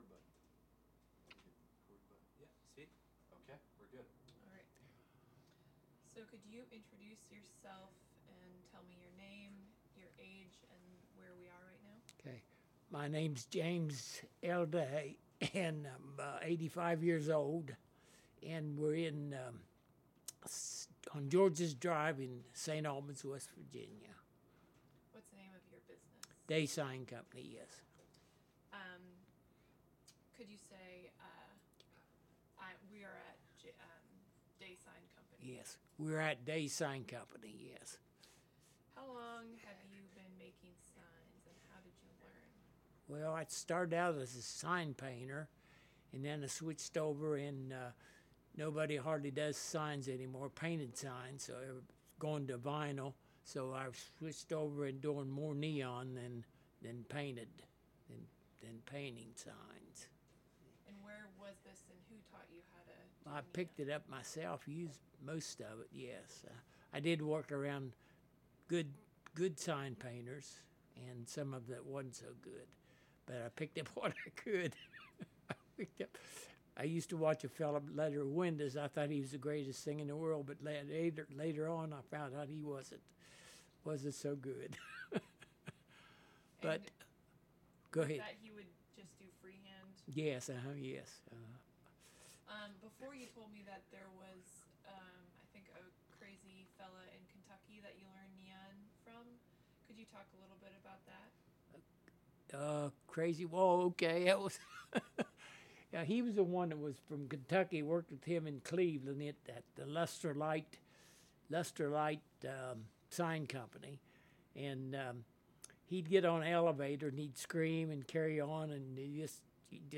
Subject: Saint Albans (W. Va.) , Tube bending , and Neon signs